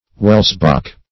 Welsbach \Wels"bach\, a.